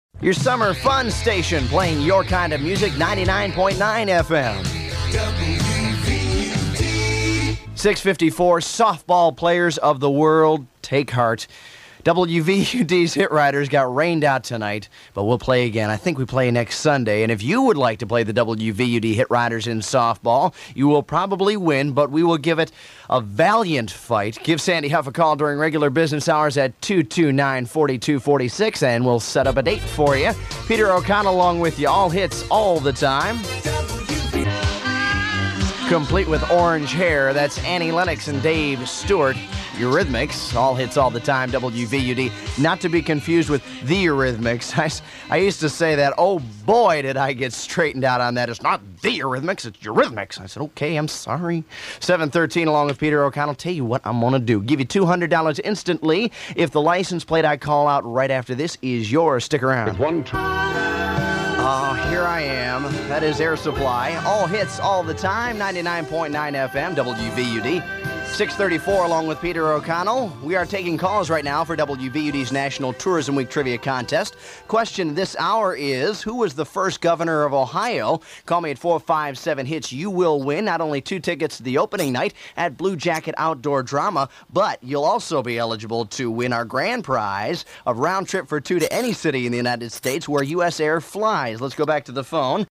I came across some old reel to reels of air checks and production demos from WVUD. The one I have has some technical problems that maybe I can fix but the rest of the world can probably only handle a minute of my God awful jock talk.